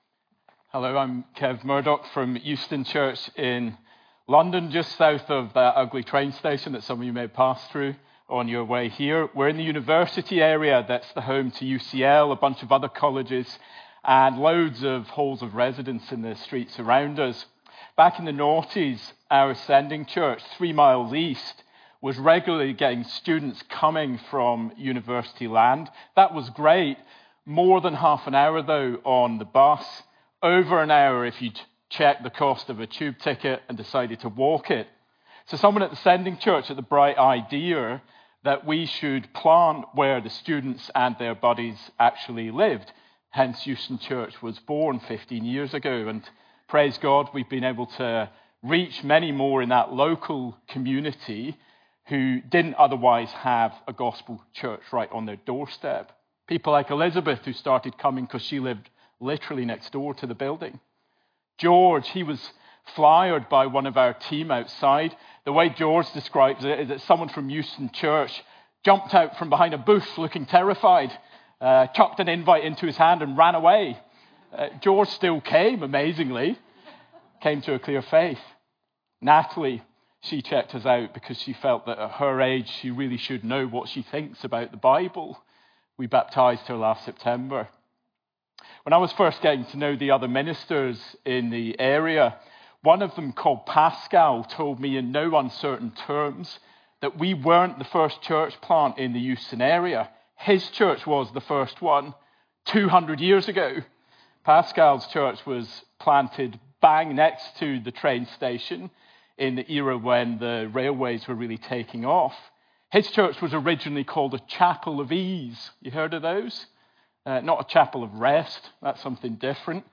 Talk audio: